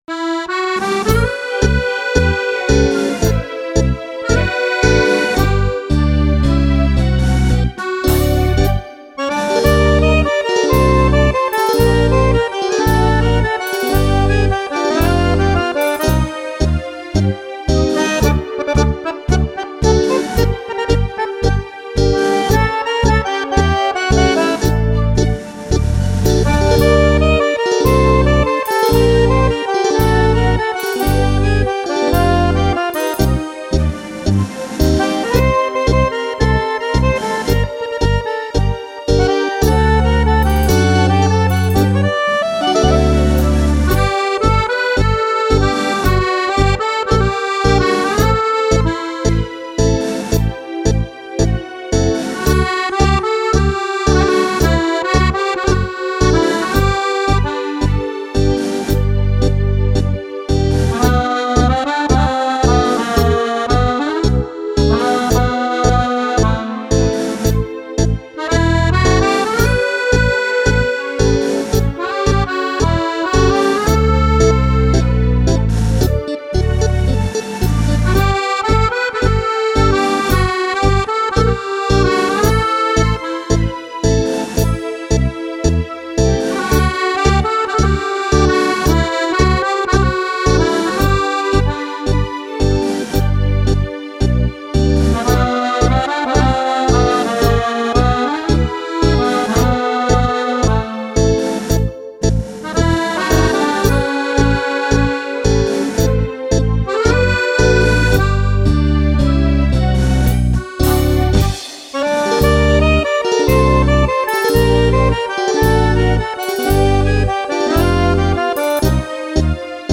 Tango
ballabili per fisarmonica